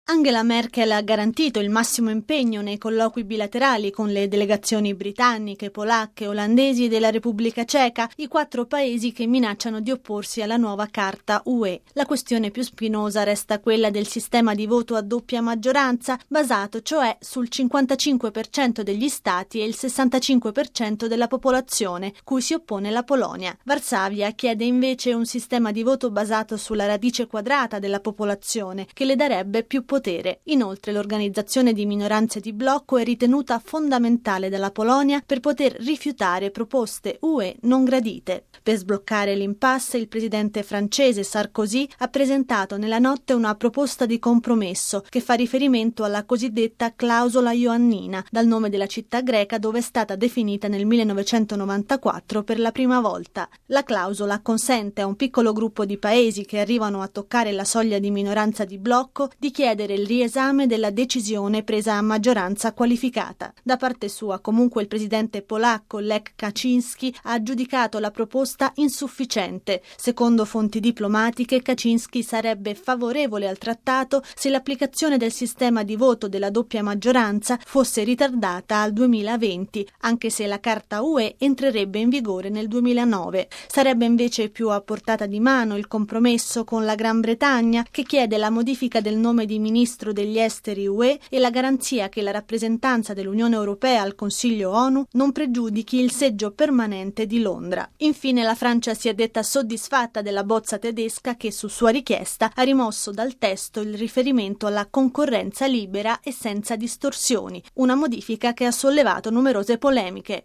La presidenza tedesca presenterà nel pomeriggio, e solo quando avrà ragionevoli possibilità di successo, una nuova proposta di compromesso. Il servizio